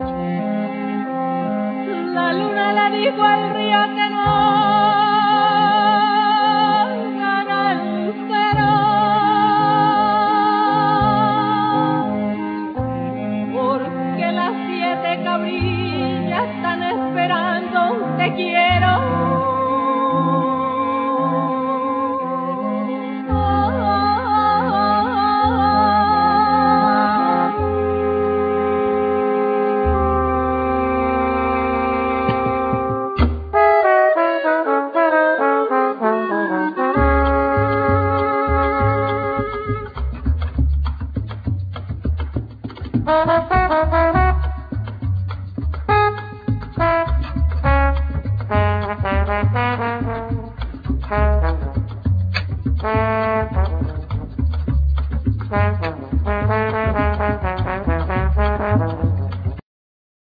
Voice, Maracas
Tambora, Llamador, Redoblante
Alegre, Platillos, Guache
Accoustic bass
Cello
Trombone